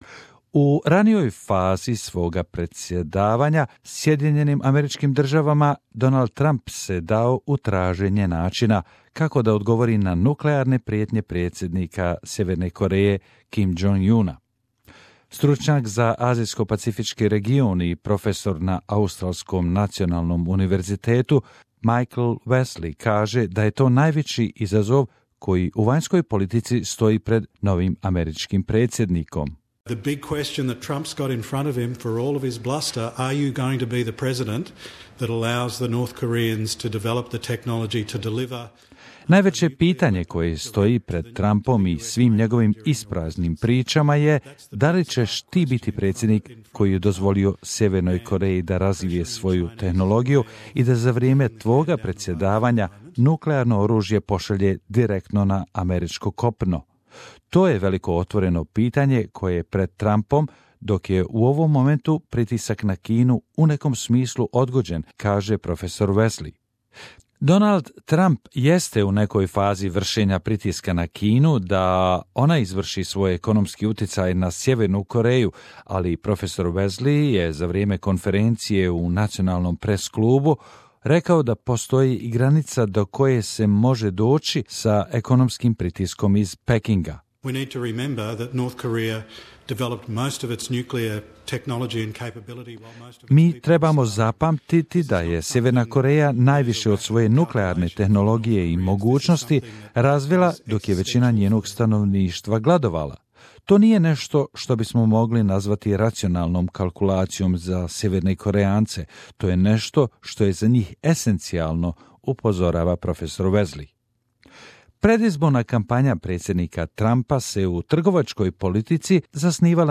The new president's approach to the Asia Pacific has been the subject of much debate as trade deals are renegotiated and tension over North Korea escalates. This Wednesday several leading Australian academics have addressed the National Press Club in Canberra, analysing the impact of the Trump presidency on the region.